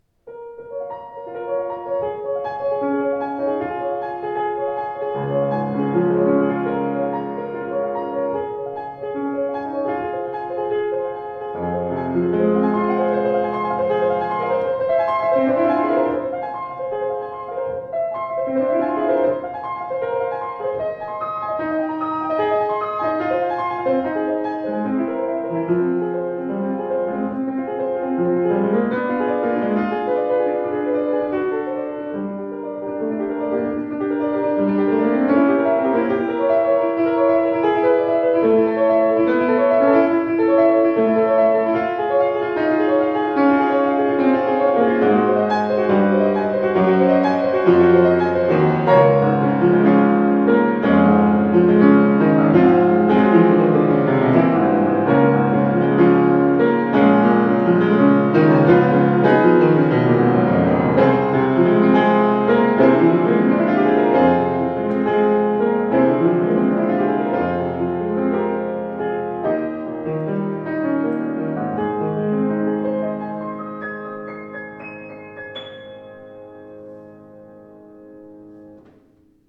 Klangproben eines anderen, baugleichen Steinway Z: